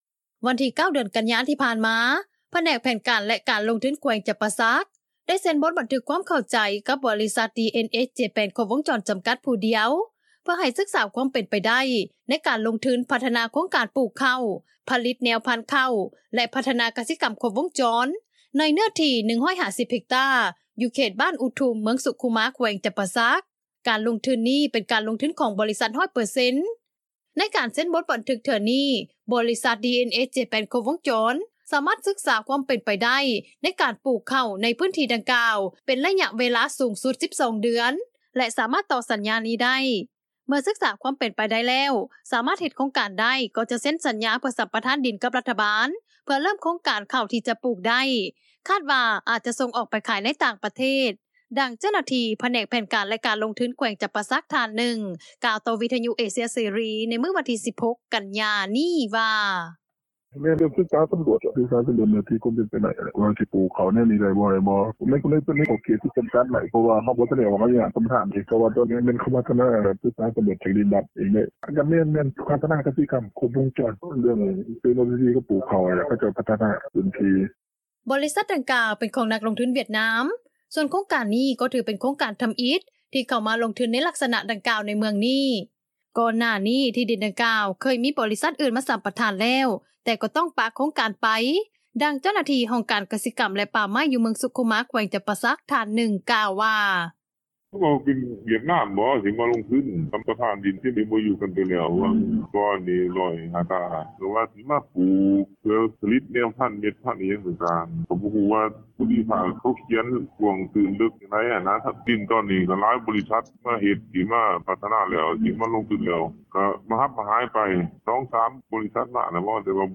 ຂະນະທີ່ ຊາວບ້ານອຸທຸມ ເມືອງສຸຂຸມາ ແຂວງຈໍາປາສັກ ນາງໜຶ່ງ ກ່າວວ່າ ຍັງບໍ່ທັນຮູ້ວ່າ ຈະມີໂຄງການດັ່ງກ່າວ ມາເຮັດການສໍາຫຼວດຄວາມເປັນໄປໄດ້ ໃນເຂດບ້ານເທື່ອ ສໍາລັບລາວນັ້ນ ເຂົ້າທີ່ປູກ ແມ່ນເປັນເຂົ້າພັນແດງ ປັດຈຸບັນ ນໍ້າຂອງມີລະດັບເພີ່ມສູງຂຶ້ນ ເຮັດໃຫ້ເຮືອນ ແລະນາຂອງປະຊາຊົນບາງສ່ວນ ຖືກນໍ້າຖ້ວມ, ດັ່ງນາງກ່າວວ່າ: